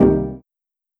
Error2.wav